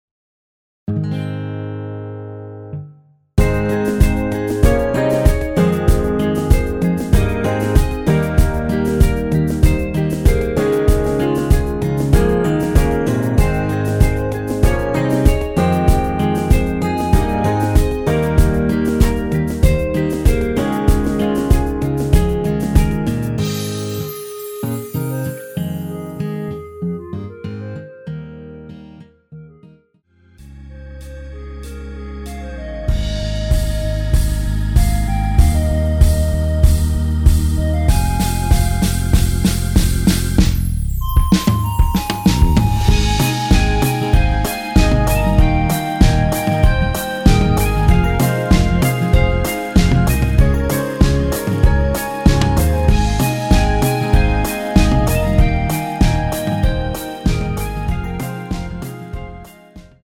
(-2) 내린 멜로디 포함된 MR 입니다.
전주 없이 시작 하는 곡이라 1마디 전주 만들어 놓았습니다.(미리듣기 참조)
Ab
앞부분30초, 뒷부분30초씩 편집해서 올려 드리고 있습니다.
중간에 음이 끈어지고 다시 나오는 이유는